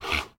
Minecraft Version Minecraft Version latest Latest Release | Latest Snapshot latest / assets / minecraft / sounds / mob / horse / donkey / idle2.ogg Compare With Compare With Latest Release | Latest Snapshot